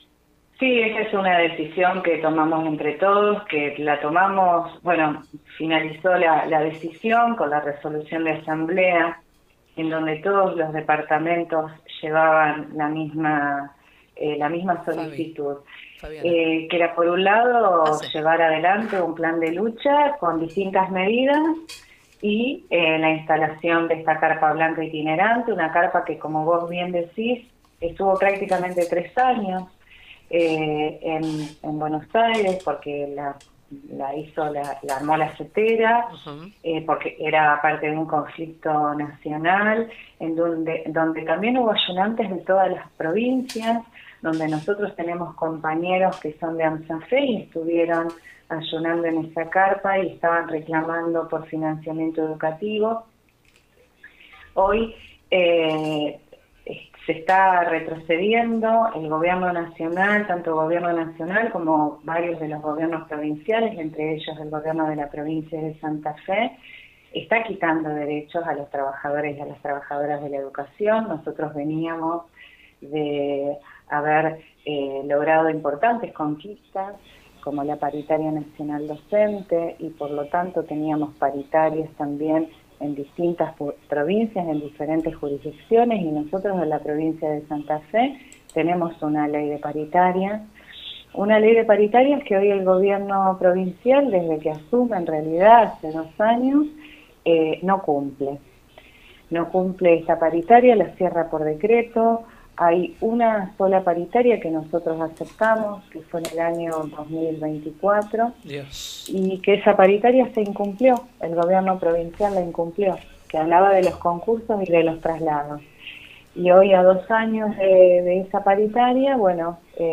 En diálogo con el programa «Con Voz» de Radio Nueva Estrella 102.9